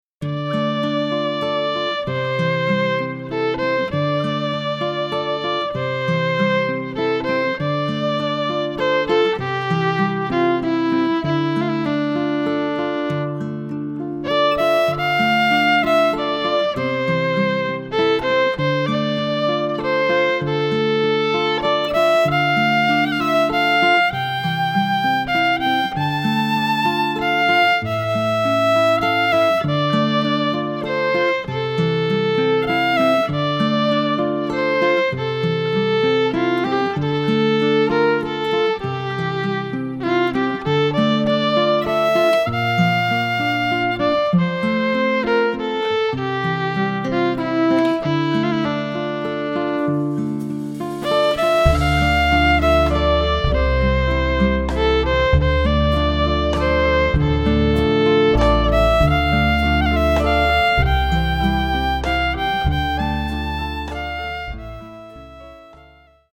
to the two heartbreakingly beautiful airs